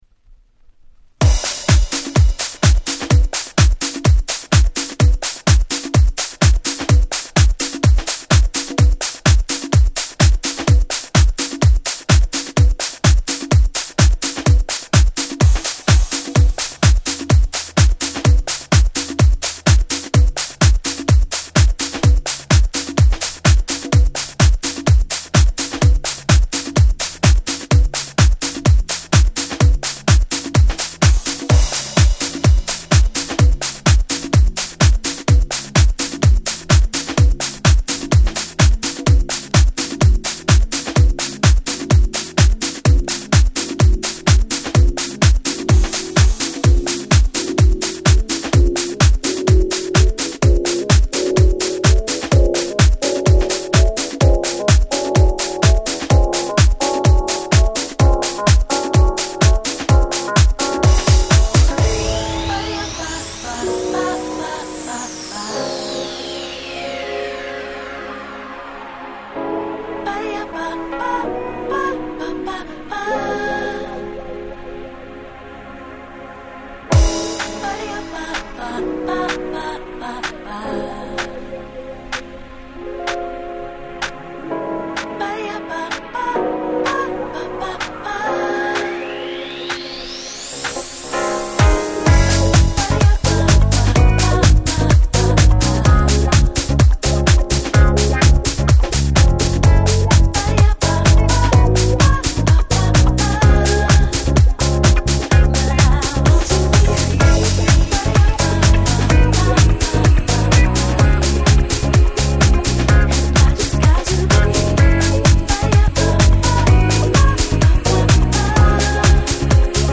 ヴォーカルハウス